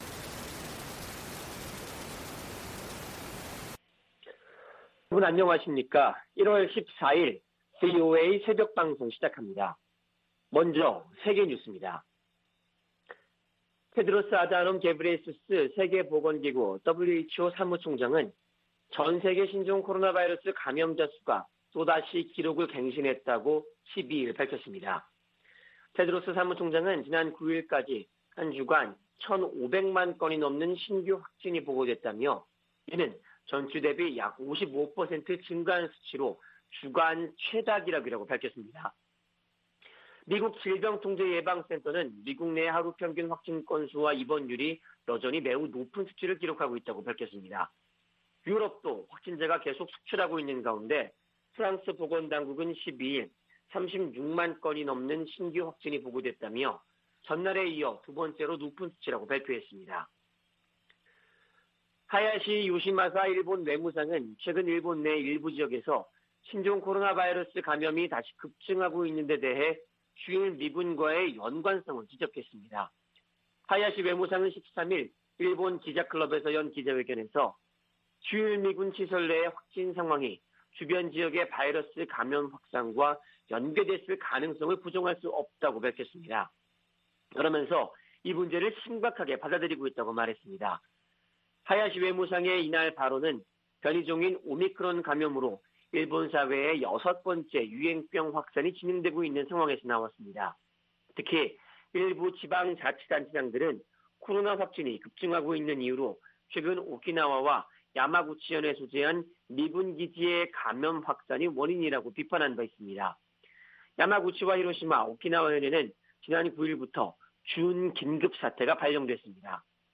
VOA 한국어 '출발 뉴스 쇼', 2021년 1월 14일 방송입니다. 미국이 북한 탄도미사일 발사에 대응해 유엔에서 추가 제재를 추진합니다. 미국 정부가 북한 미사일 관련 물품을 조달한 북한 국적자 6명과 러시아인 등을 제재했습니다. 북한이 김정은 국무위원장 참관 아래 극초음속 미사일 시험발사 성공을 발표하면서 미-북 간 갈등이 고조되고 있습니다.